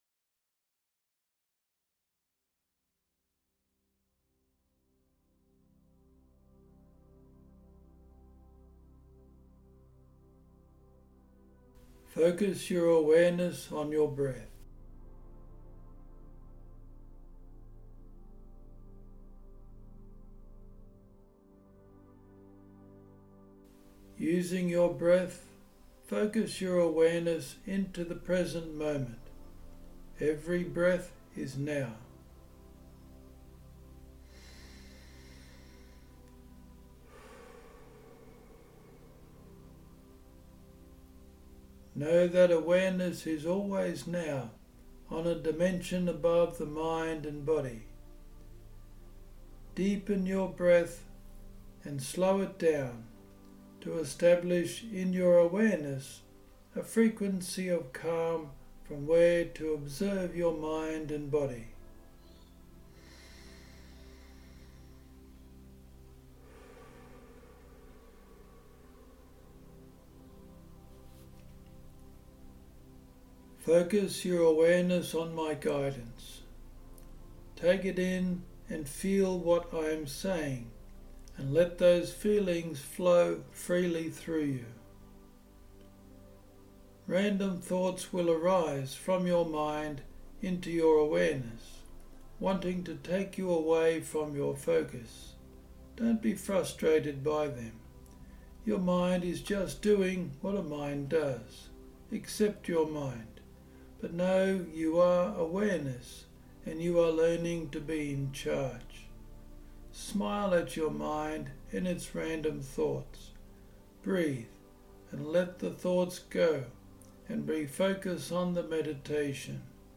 As Awareness, during the guided meditation, you will be learning how to reprogram your mind to acceptance and compassion, while healing self-judging thoughts. I have left time at the end of this meditation with the background music still running for you to do your own practice.